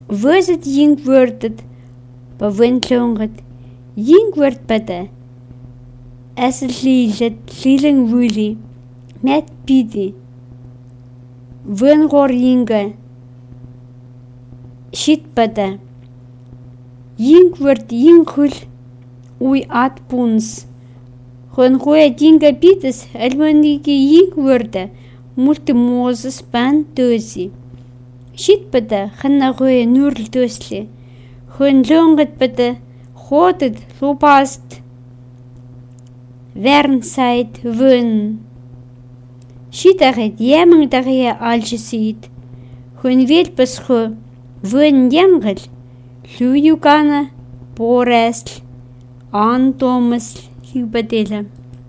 prose (pro)